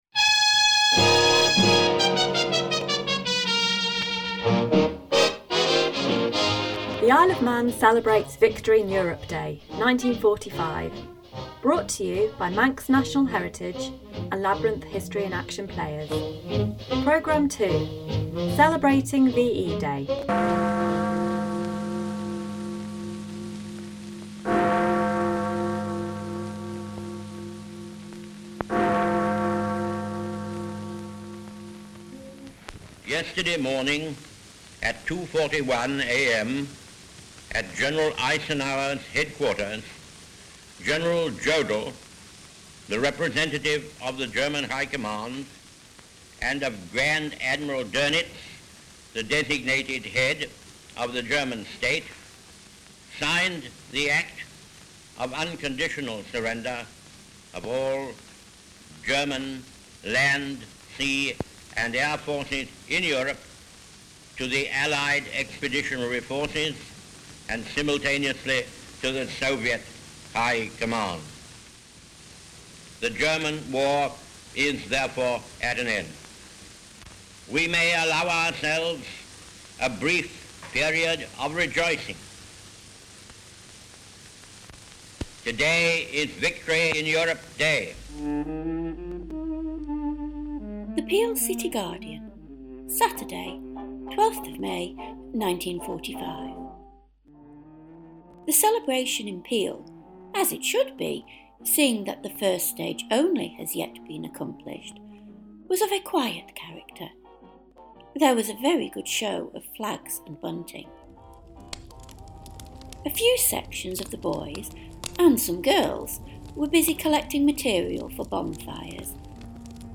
As we mark the 80th anniversary of VE Day, Manx National Heritage Labyrinth History in Action Players bring you some of the sounds and reports of VE Day in the IOM.